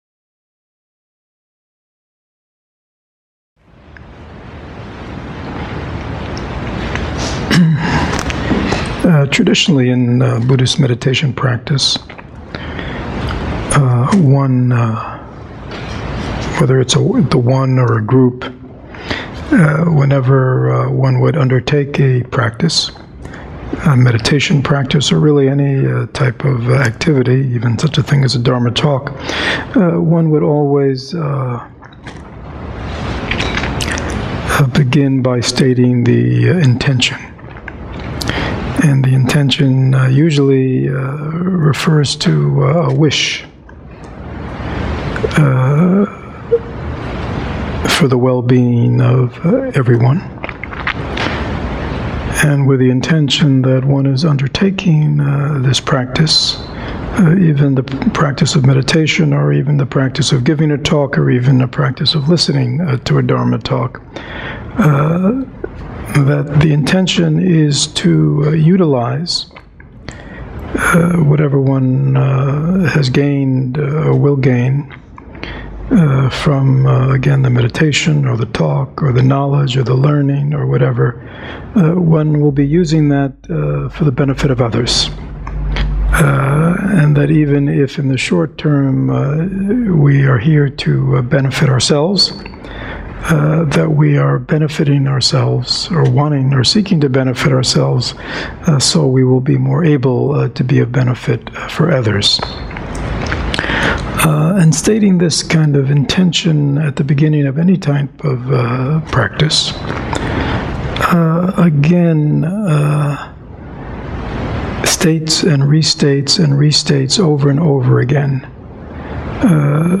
How Can I Energize My Practice Sangha Talk | August 2013 Why can't I seem to meditate consistently?